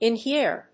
発音記号
• / ɪnhíɚ(米国英語)